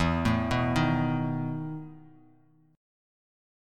EM7sus4 chord